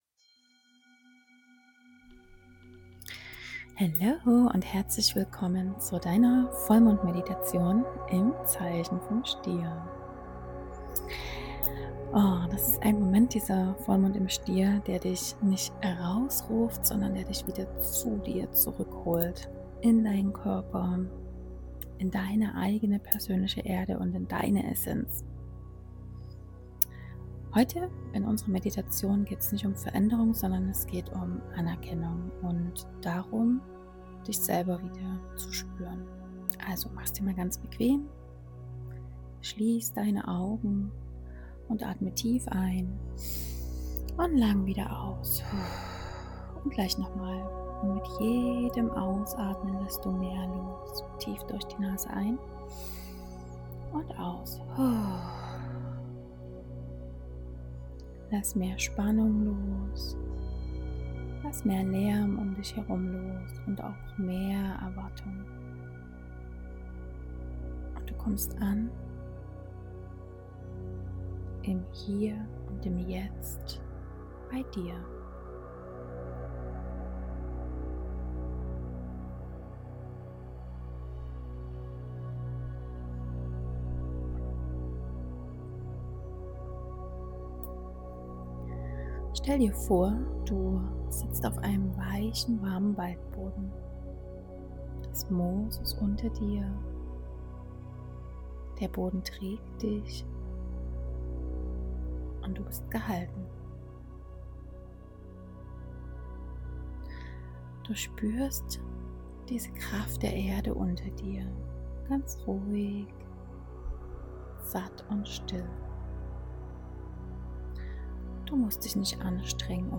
achtsam rebellischer Safe Space - Vollmond Meditation